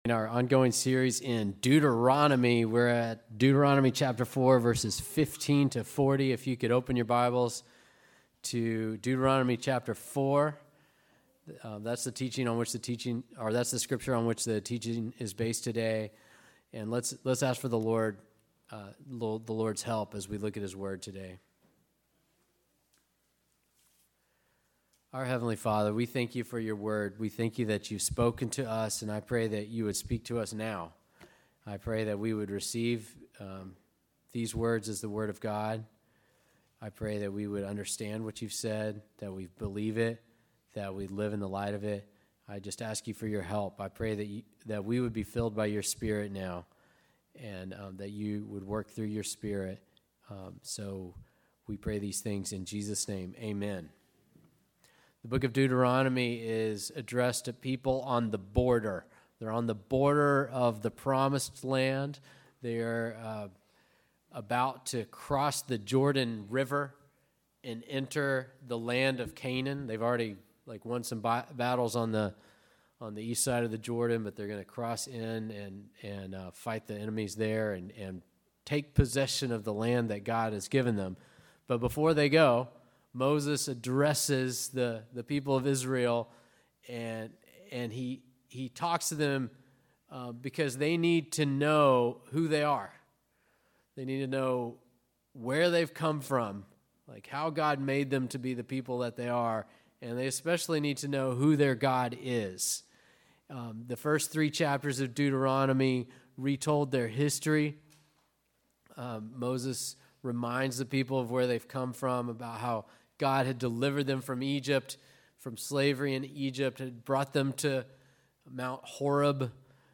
Listen to sermons by our pastor on various topics.